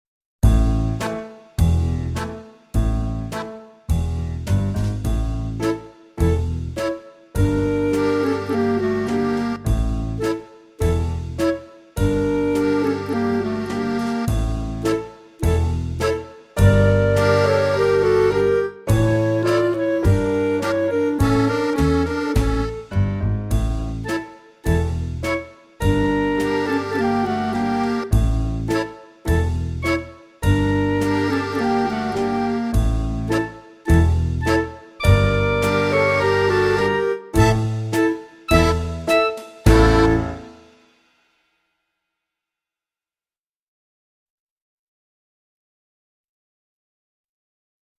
VS Jewish Wedding (backing track)